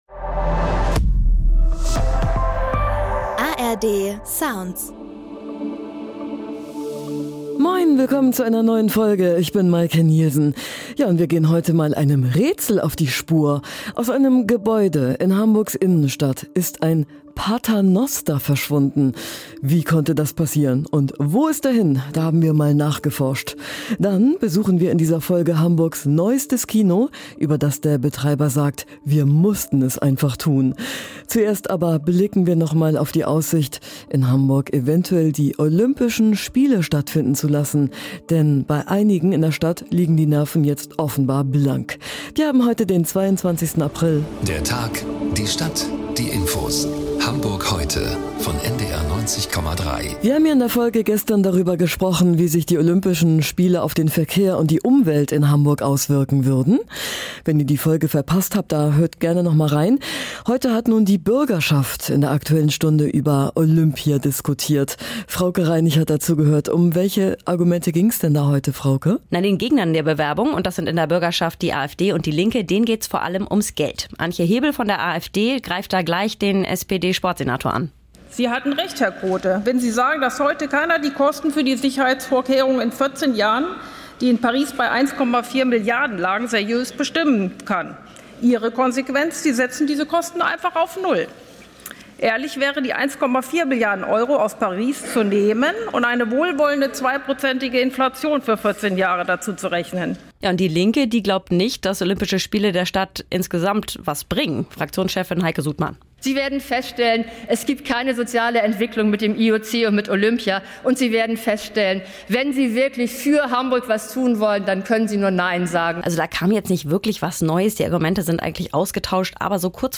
Hamburg: Ältester Paternoster der Welt verschwunden - wo ist er hin? ~ Hamburg Heute - Nachrichten Podcast